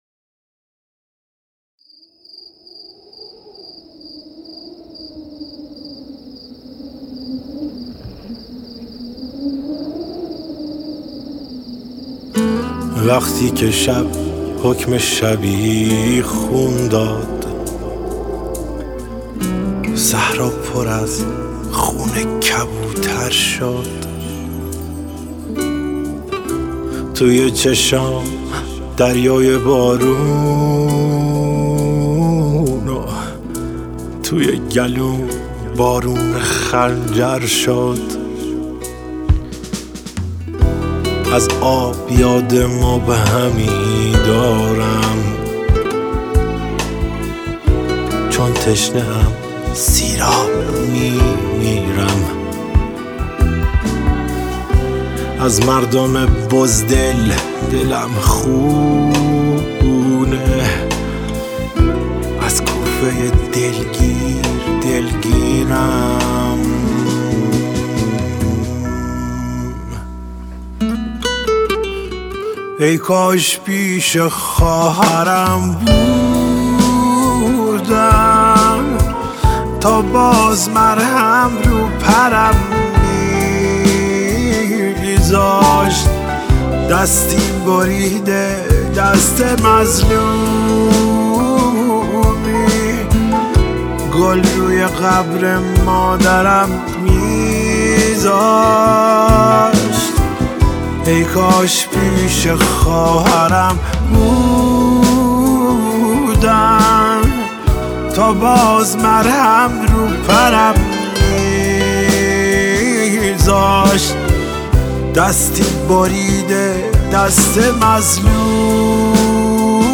گیتار
ویولن